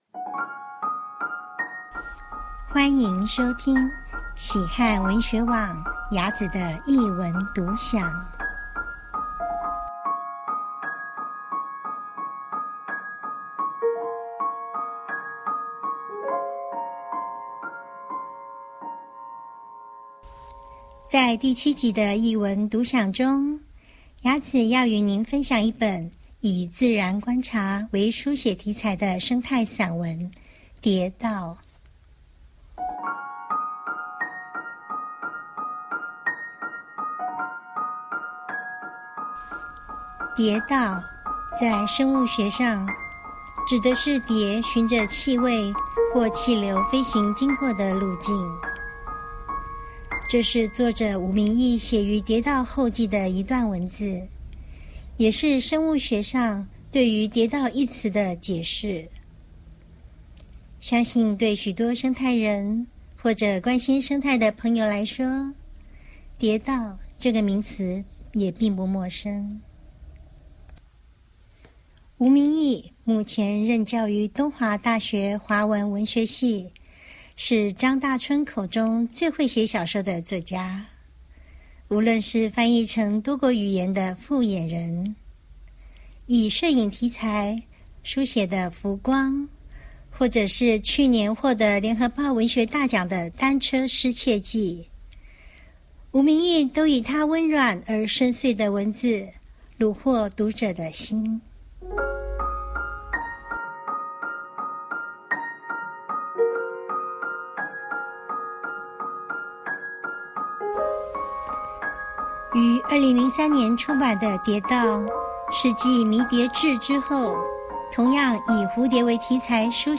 音樂演奏